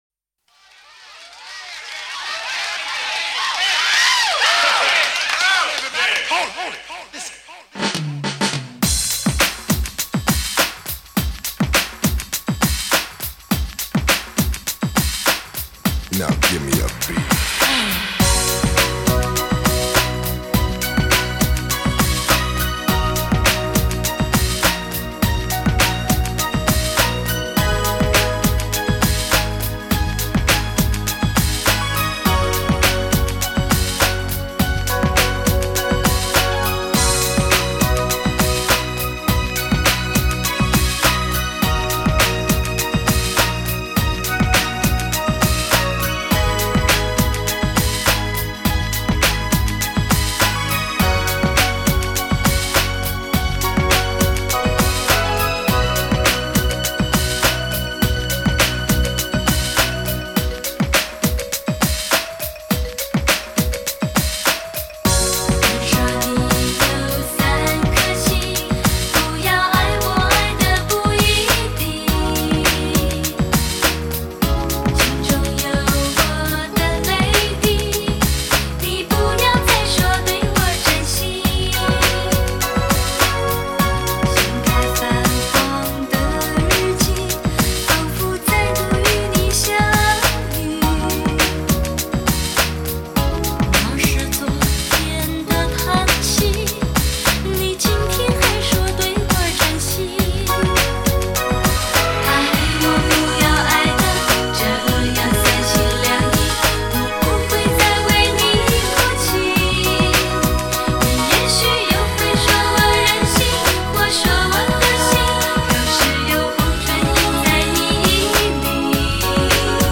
试听低品质
SAX SOUL MIX